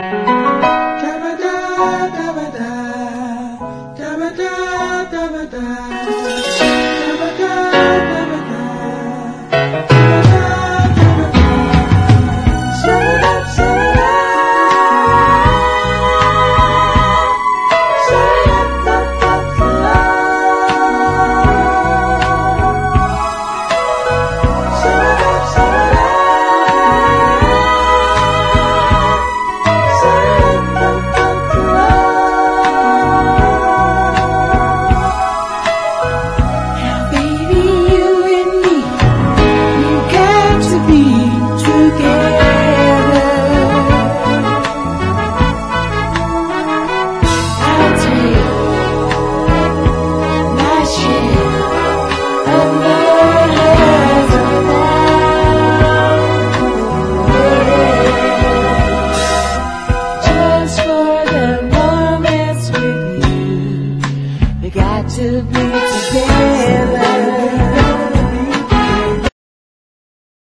¥1,680 (税込) ROCK / 80'S/NEW WAVE. / POST PUNK.